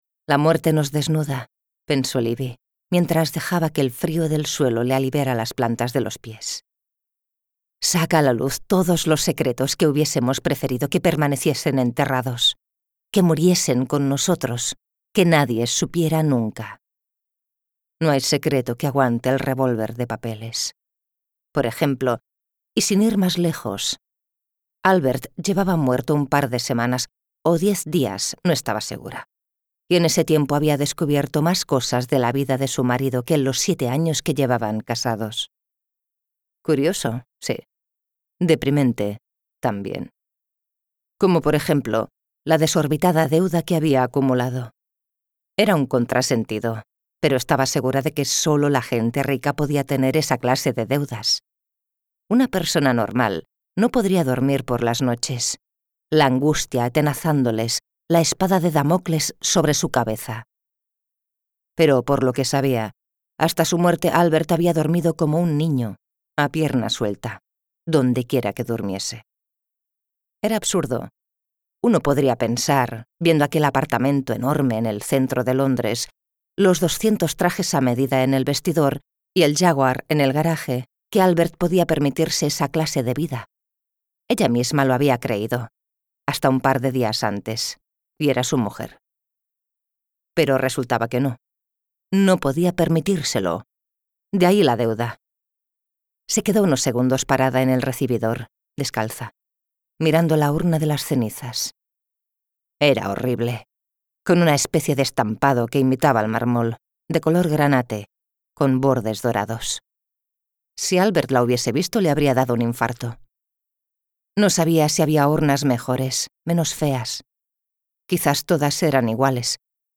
Audiolibros Novela policíaca - Sonolibro